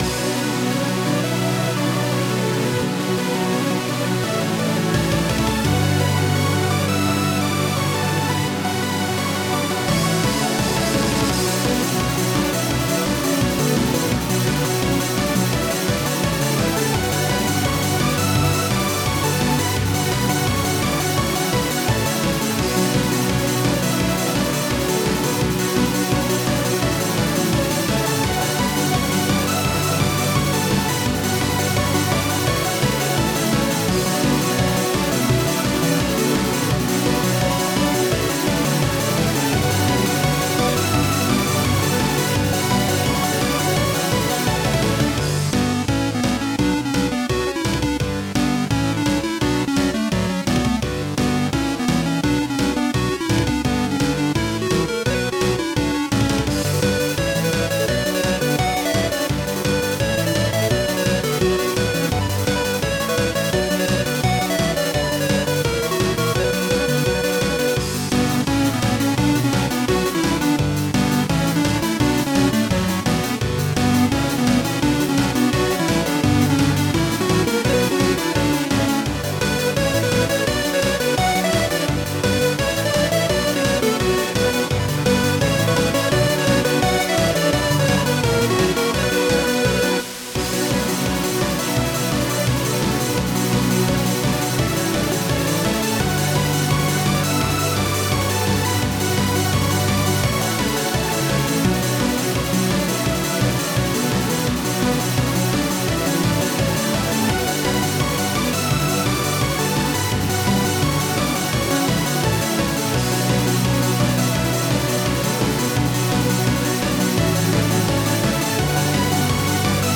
Hard Chiptune remix